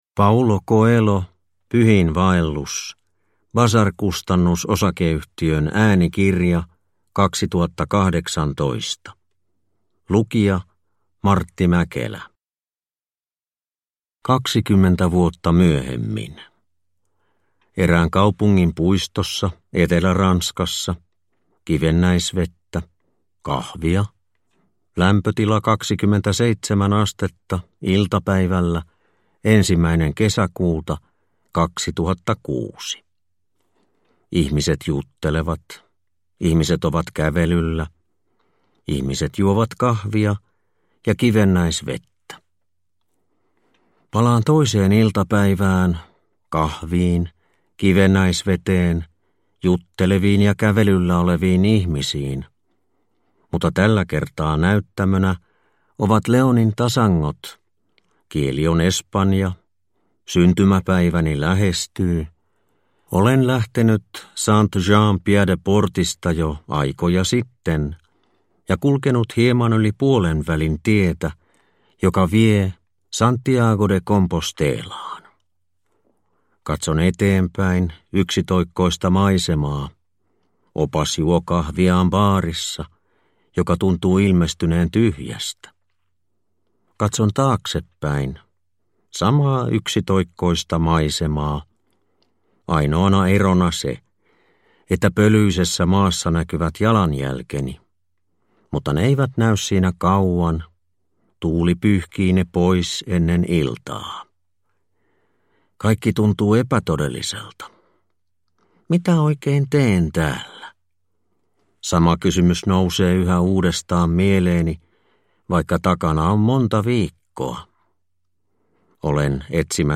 Pyhiinvaellus – Ljudbok